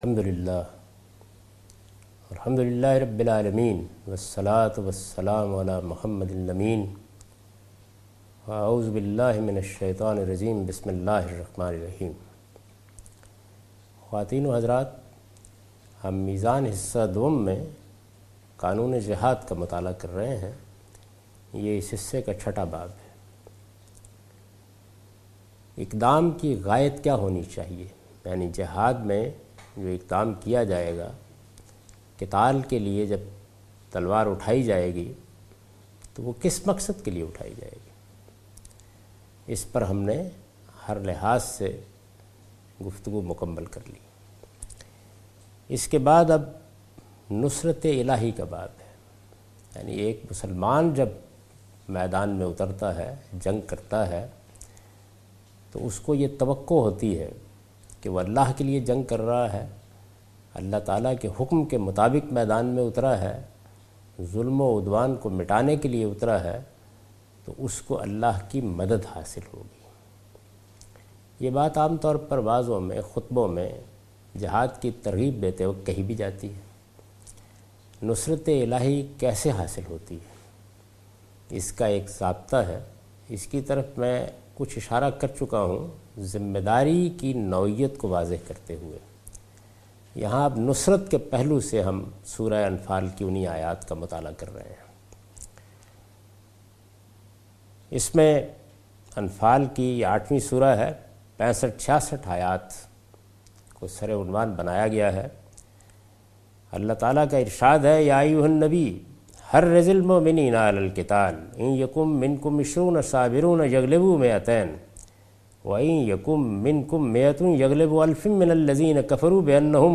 A comprehensive course taught by Javed Ahmed Ghamidi on his book Meezan.